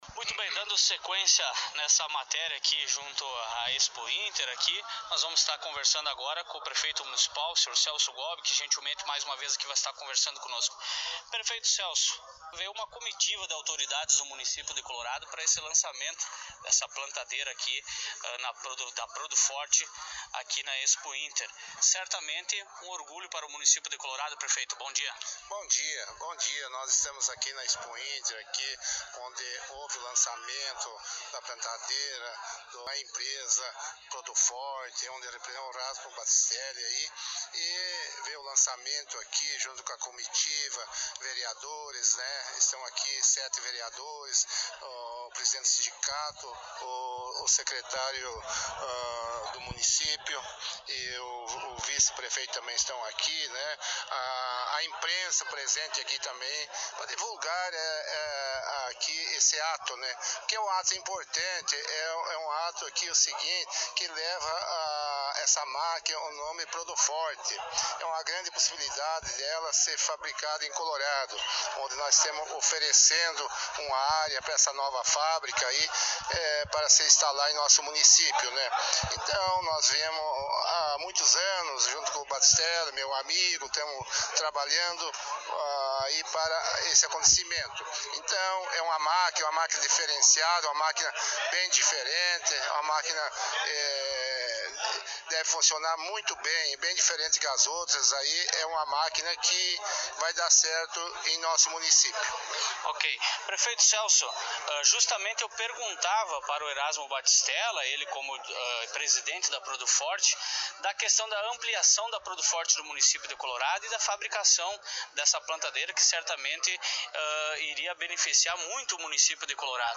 O Colorado em Foco esteve na 40ª edição da Expointer, visitando a empresa coloradense PRODUFORT, que pela quinta vez esteve participando da feira, onde a mesma esteve lançando sua Plantadeira Múltipla Produfort.
Entrevista com o Prefeito Celso Gobbi.